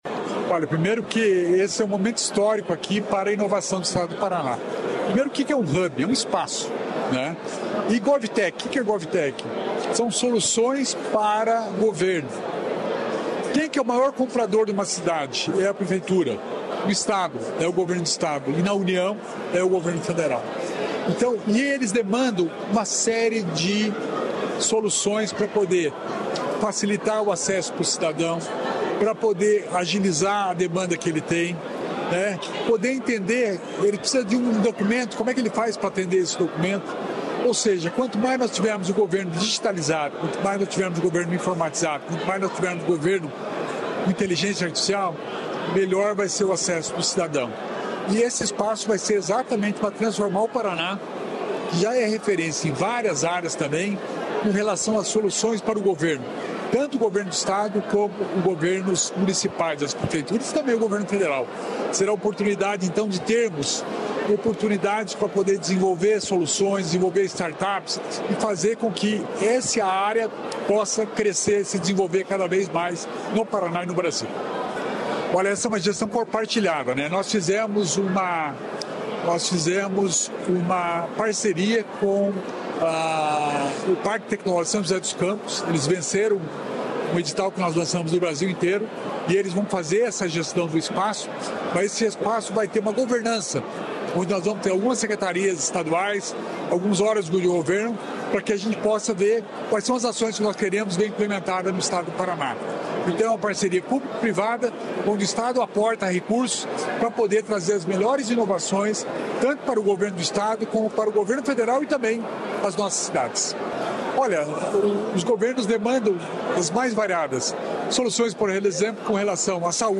Sonora do secretário de Estado da Inovação e Inteligência Artificial, Alex Canziani, sobre Hub GovTech Paraná